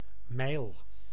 Ääntäminen
UK : IPA : /maɪ̯l/ US : IPA : /maɪ̯l/